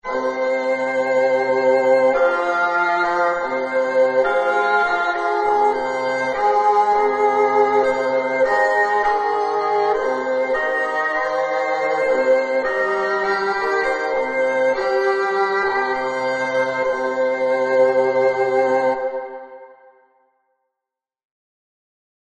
P.S. du 20/04/17 : ces mêmes décompositions de l'entier 7 peuvent engendrer une partition minimaliste
Du point de vue harmonique, c'est une bébête petite maison sur le tonnetz Maison sur le tonnetz
mais le rythme est plus subtil (enregistrement MP3 de 180 Ko)